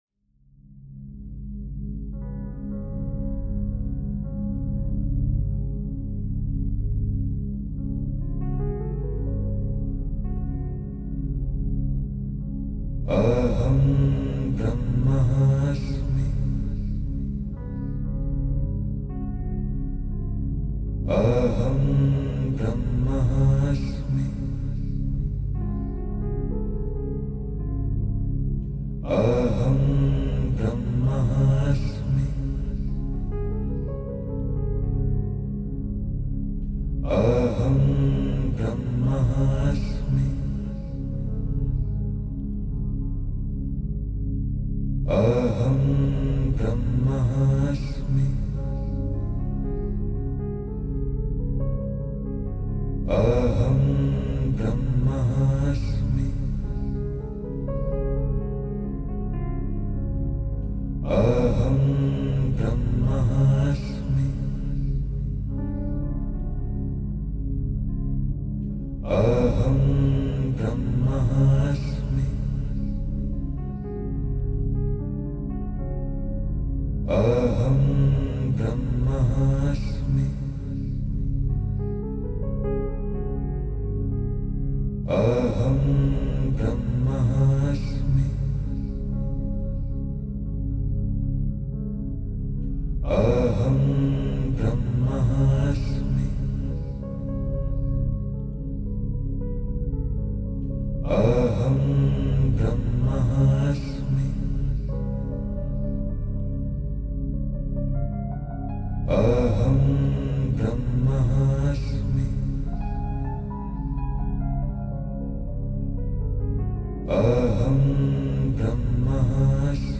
AHAM-BRAHMASMI-MANTRA-108-Times.mp3